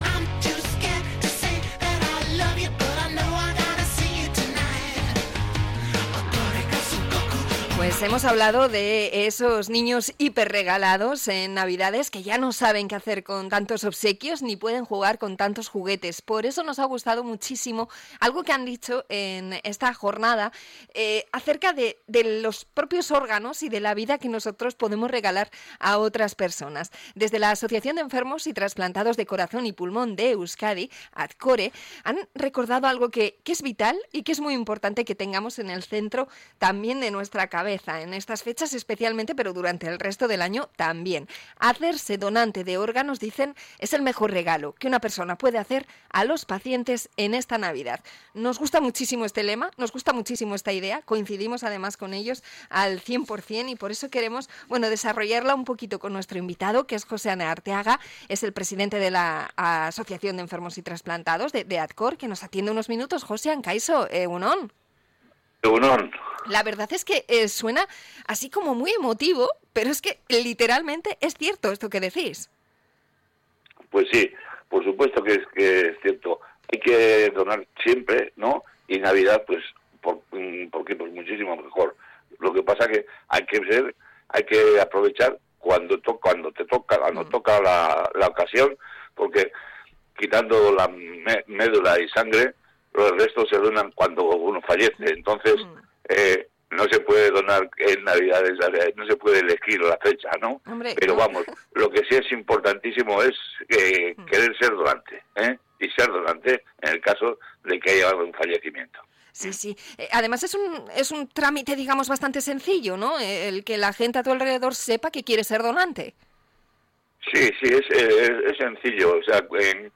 Entrevista a la asociación de donantes ATCORE